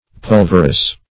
Search Result for " pulverous" : The Collaborative International Dictionary of English v.0.48: Pulverous \Pul"ver*ous\, a. [Cf. L. pulvereus, from pulvis, pulveris, dust, powder.]